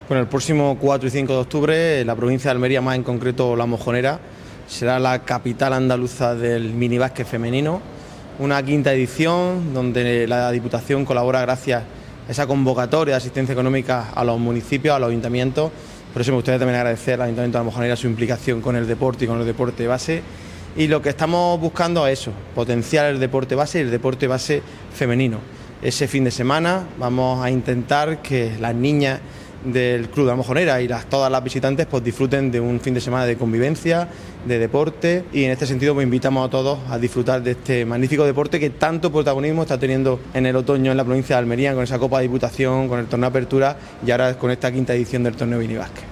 29-09_diputado_torneo_femenino_basket.mp3